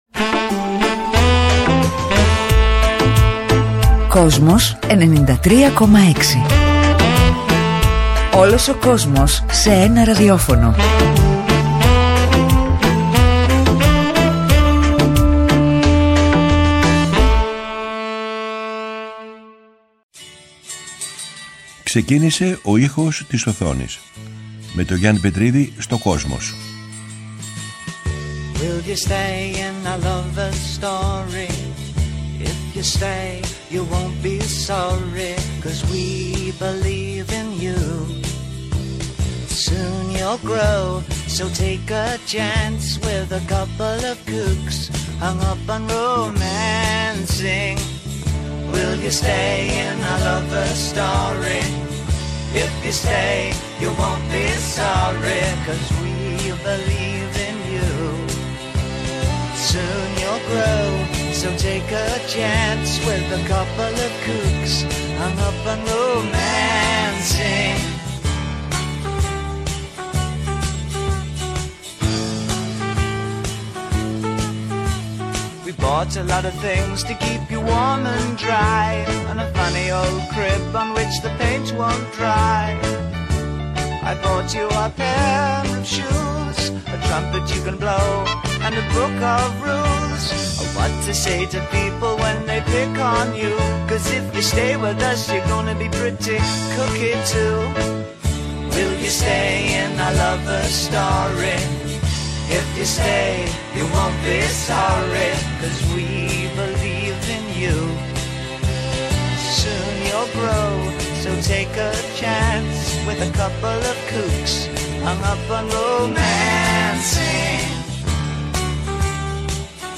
Στην ταινία πρωταγωνιστούν, Τζένιφερ Λόρενς , Ρόμπερτ Πάτινσον, ΛαΚιθ Στάνφιλντ, Νικ Νόλτε , Σίσι Σπέισεκ κ.α. Κάθε Κυριακή 18:00-19:00 ο Γιάννης Πετρίδης παρουσιάζει μία σειρά αφιερωματικών εκπομπών για το Kosmos, με τον δικό του μοναδικό τρόπο.